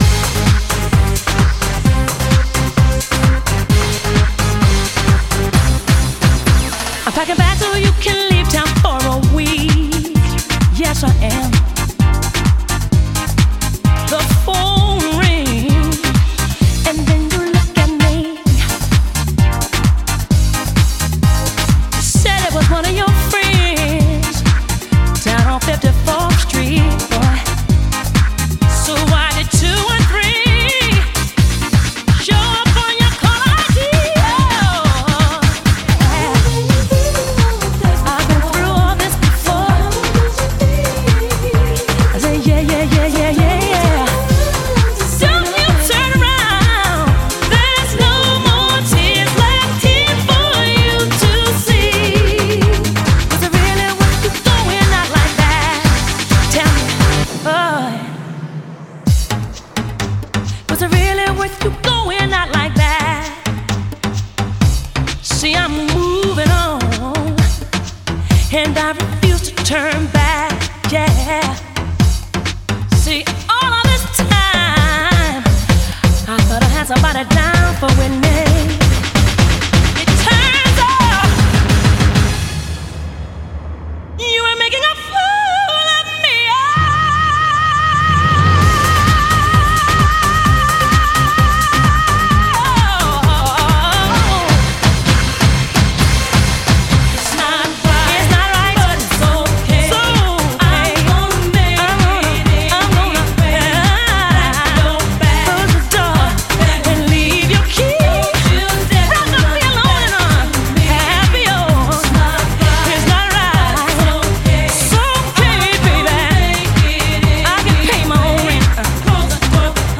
BPM130
Enjoy the vocal acrobatics during the break.